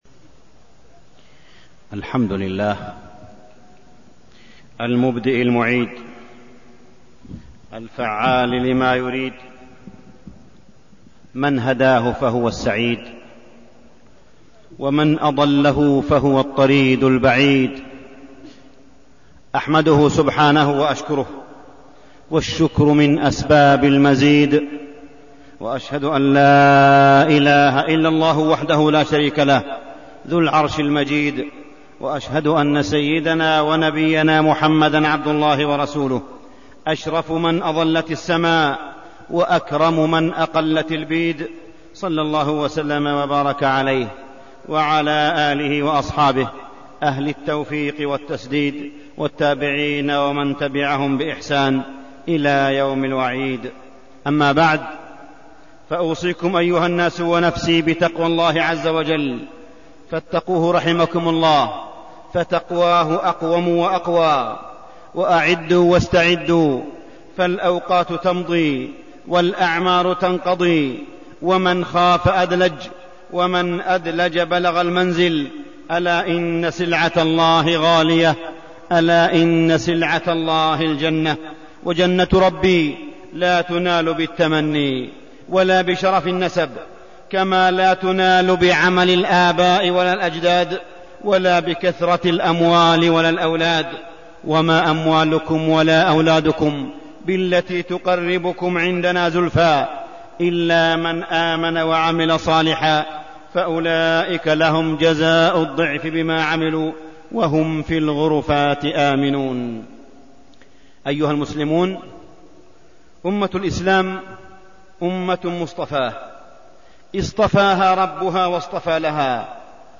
تاريخ النشر ١٩ ربيع الثاني ١٤١٨ هـ المكان: المسجد الحرام الشيخ: معالي الشيخ أ.د. صالح بن عبدالله بن حميد معالي الشيخ أ.د. صالح بن عبدالله بن حميد يوم الجمعة فضله ومكانته